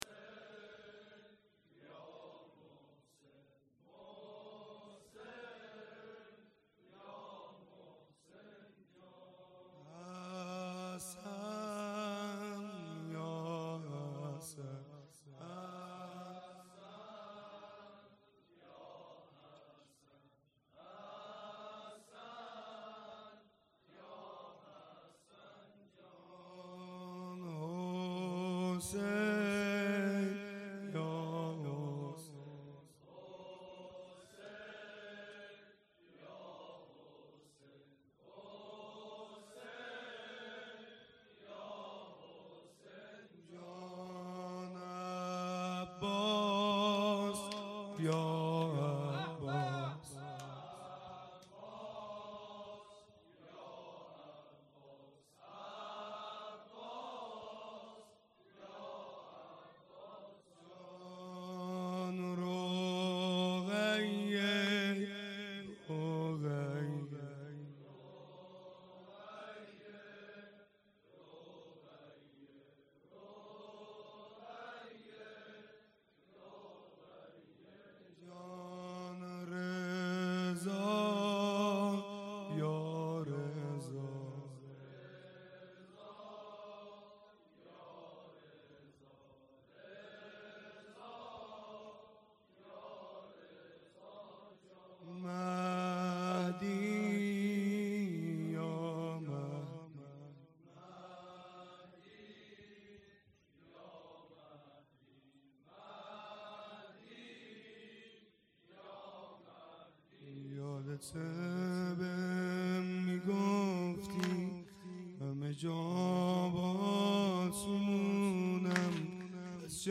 مراسم شب اول فاطمیه دوم